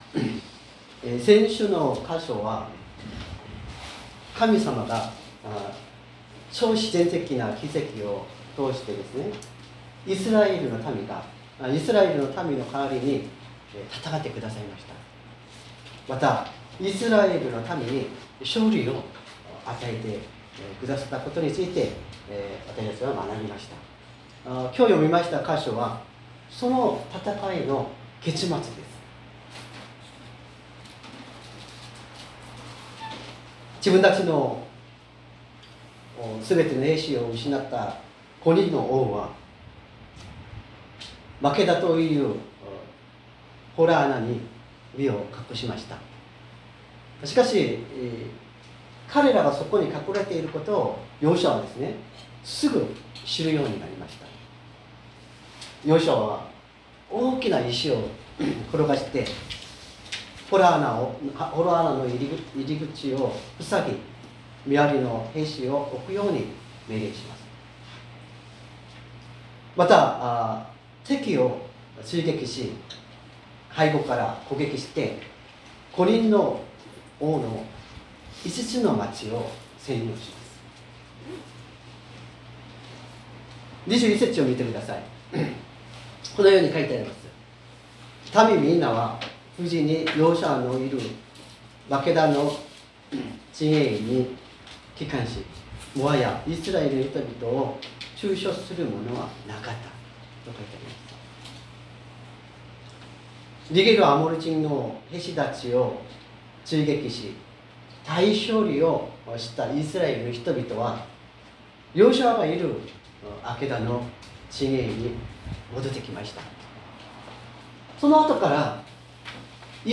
礼拝説教を録音した音声ファイルを公開しています。